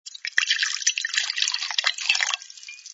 sfx_drinks_pouring03.wav